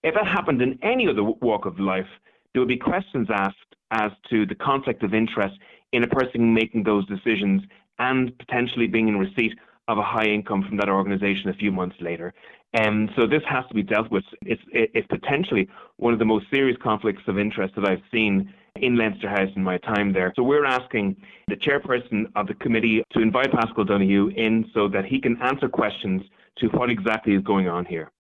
Peadar Tóibín says giving extra money before taking up a job with the same organisation raises major questions……….